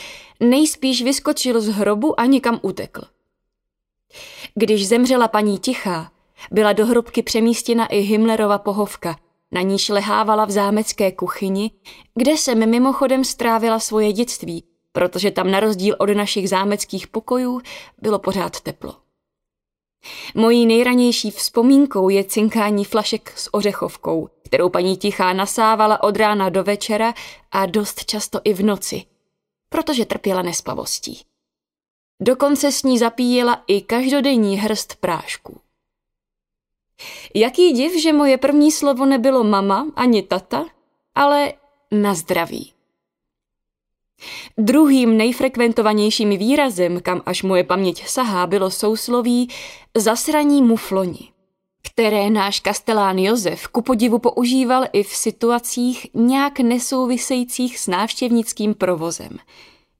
Audiobook
Read: Naďa Konvalinková